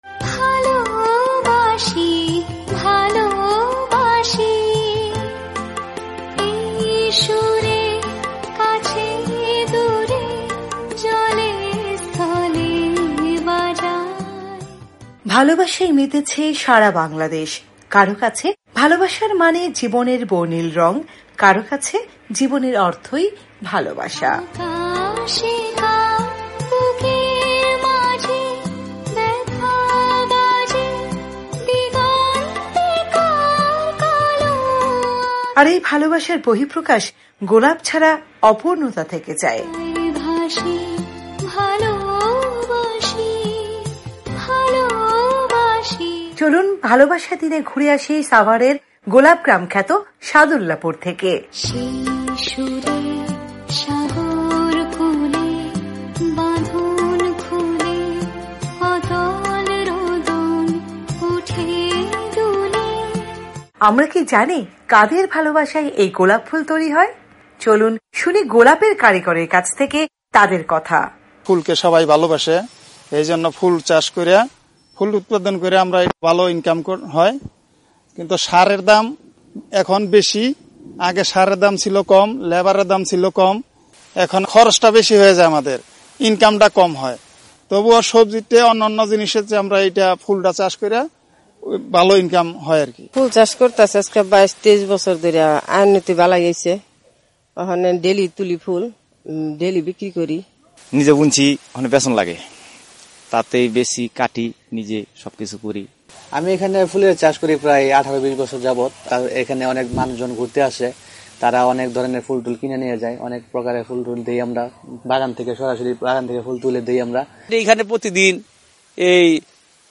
চলুন শুনি গোলাপের কারিগরদের কাছ থেকে তাদের কথা।
গোলাপের টানে গোলাপ গ্রামে ঘুরতে আসা দর্শনার্থীদের কাছ থেকে শুনি গোলাপ ভাবনা।